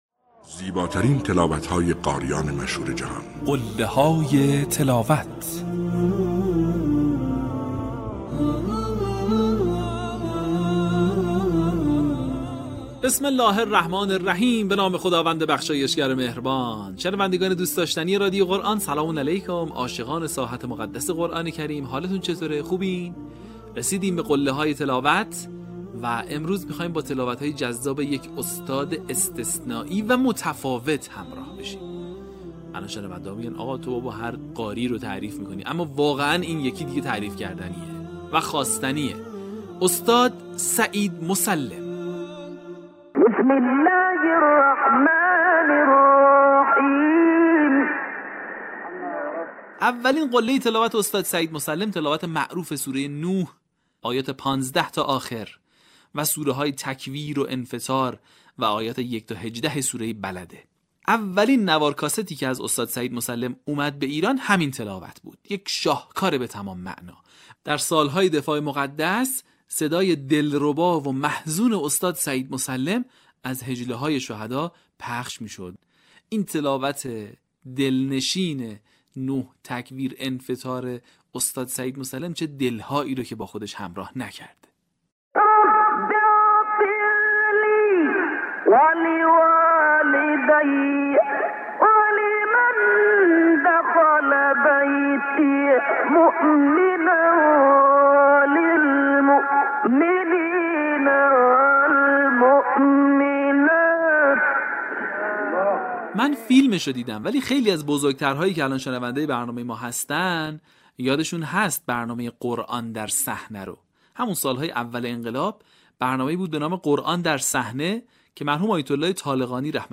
به همین منظور برترین و برجسته‌ترین مقاطع از تلاوت‌های شاهکار قاریان بنام جهان اسلام که مناسب برای تقلید قاریان است با عنوان «قله‌های تلاوت» ارائه و بازنشر می‌شود. در قسمت دوازدهم، فرازهای شنیدنی از تلاوت‌های به‌یاد ماندنی استاد سعید مسلم را می‌شنوید.